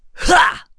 Roman-Vox_Attack1.wav